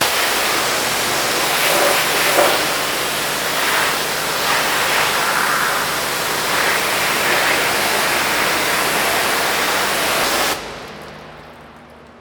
hose.wav